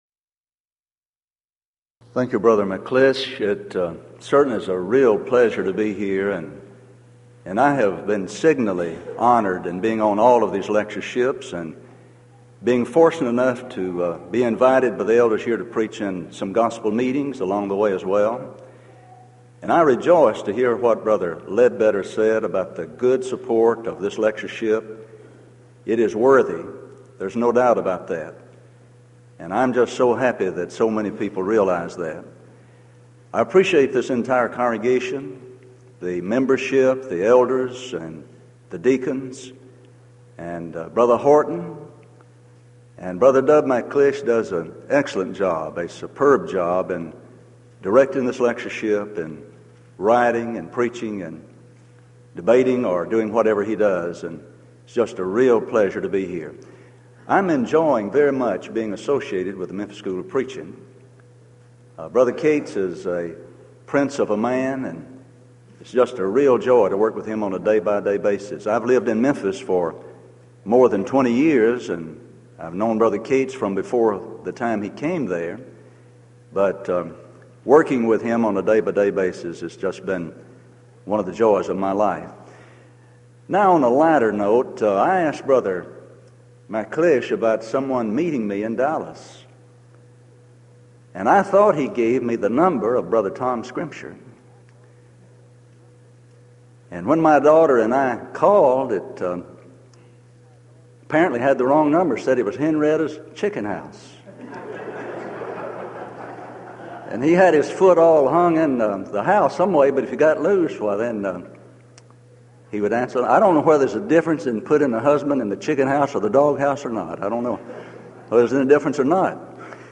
Event: 1993 Denton Lectures